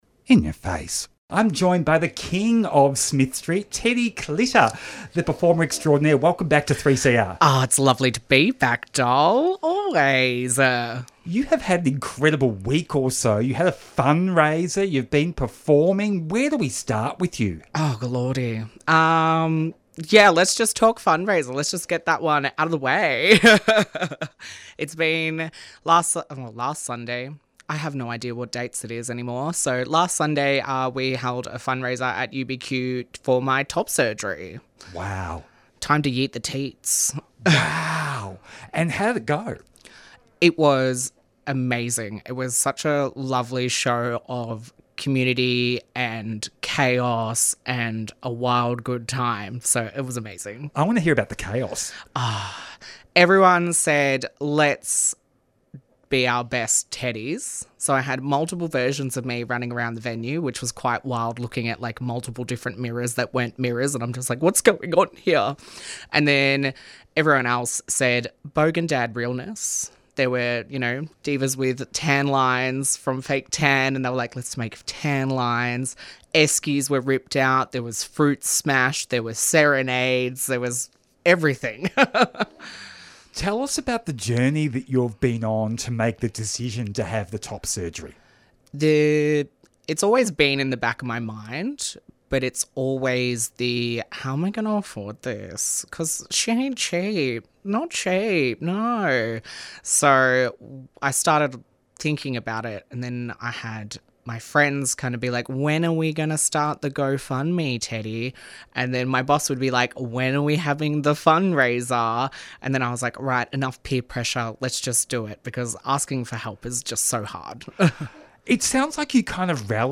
• Instagram photos and videos Tweet In Ya Face Friday 4:00pm to 5:00pm Explores LGBTIQA+ issues with interviews, music and commentary.